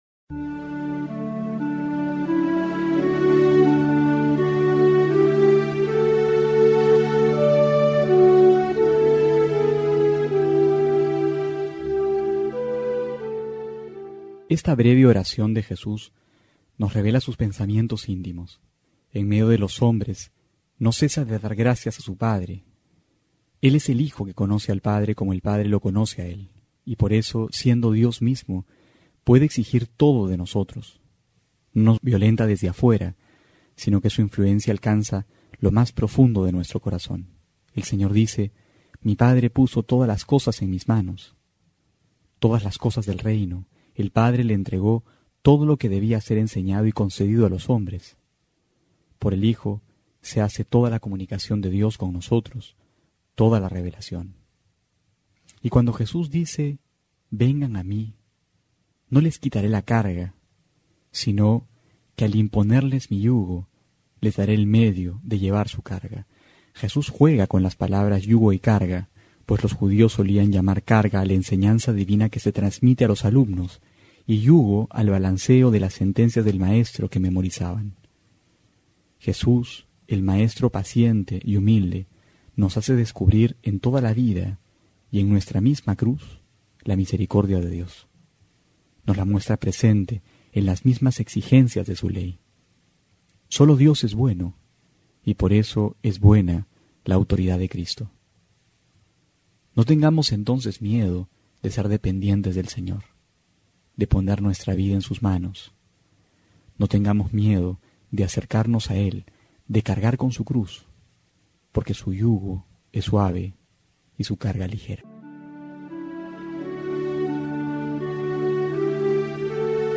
Homilía para hoy: Mateo 11,25-27
julio17-13homilia.mp3